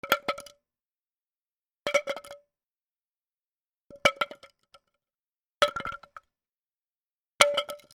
木を落とす 『カラン』